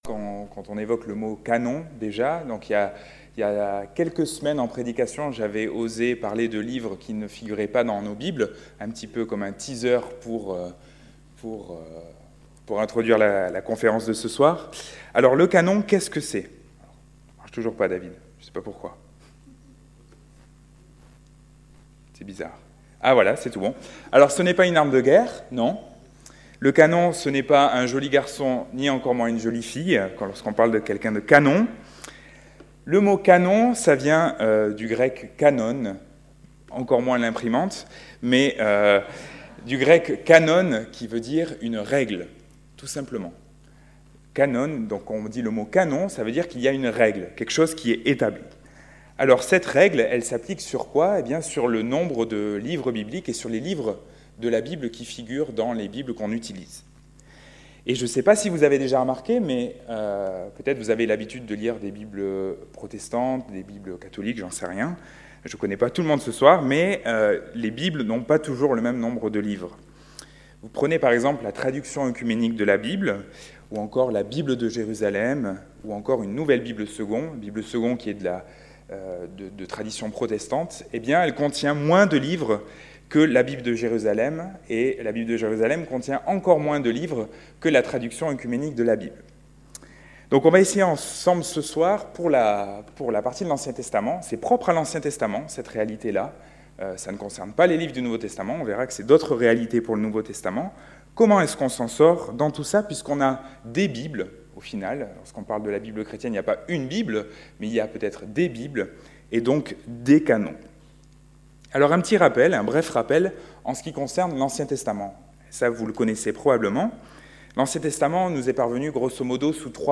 Conférence Frathéo du jeudi 14 juin 2018